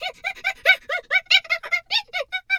hyena_laugh_03.wav